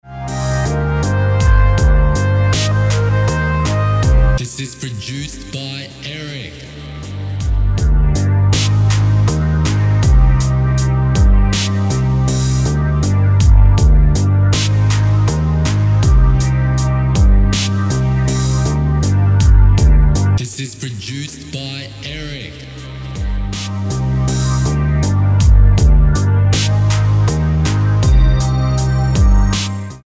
Key: C minor Tempo: 80BPM Time: 4/4 Length: 3:36